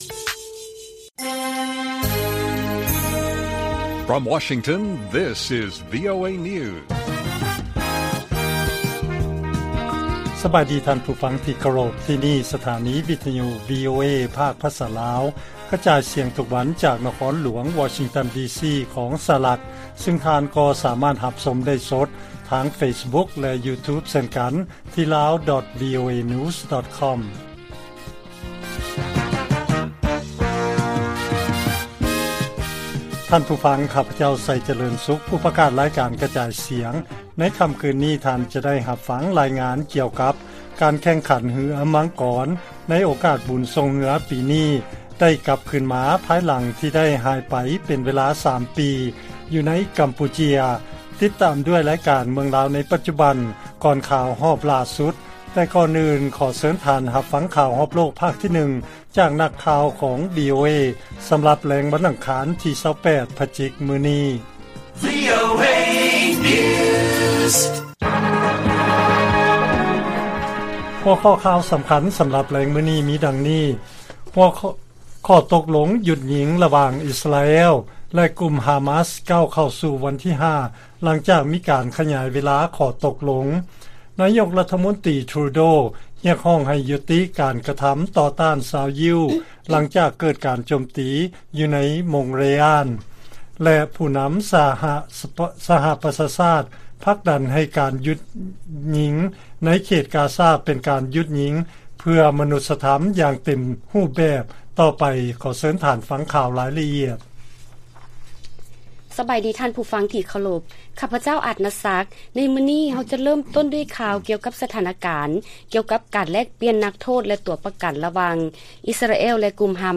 ລາຍການກະຈາຍສຽງຂອງວີໂອເອ ລາວ: ຂໍ້ຕົກລົງຢຸດຍິງ ລະຫວ່າງອິສຣາແອລ ແລະກຸ່ມຮາມາສ ກ້າວເຂົ້າສູ່ວັນທີ 5 ຫຼັງຈາກມີການຂະຫຍາຍເວລາຂໍ້ຕົກລົງ